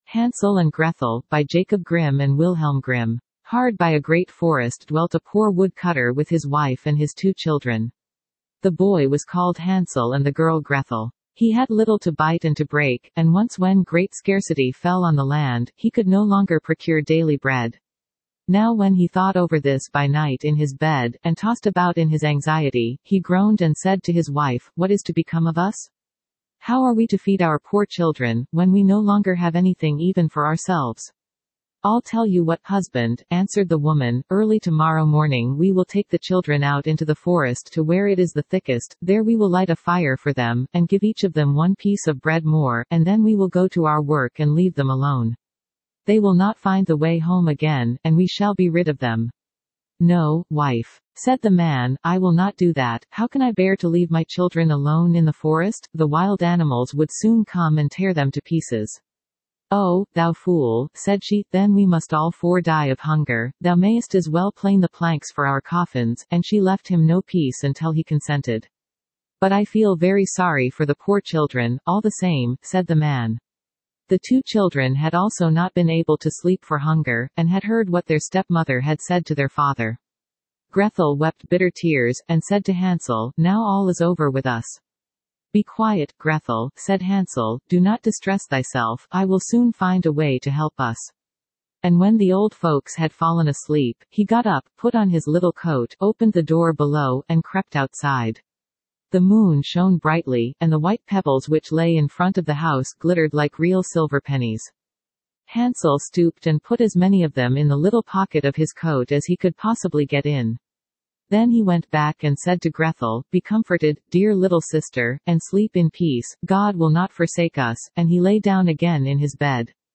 Spoken Ink « Household Tales by Brothers Grimm Hansel And Grethel Standard (Male) Download MP3 Standard (Female) Download MP3 Hard by a great forest dwelt a poor wood-cutter with his wife and his two children.
hansel-and-grethel-en-US-Standard-C-73cfe43a.mp3